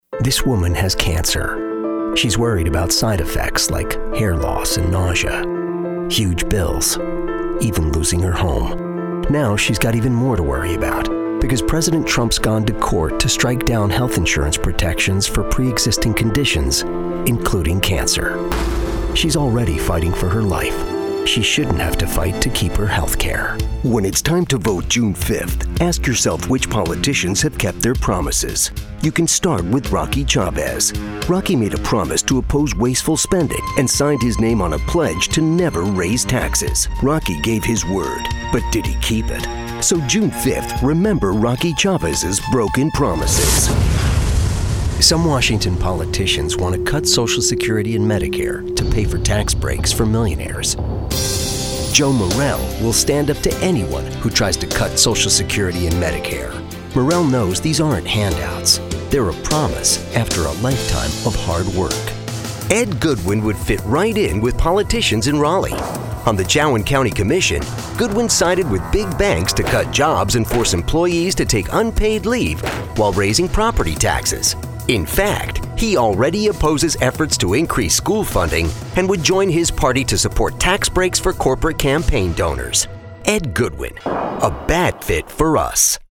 Male VOs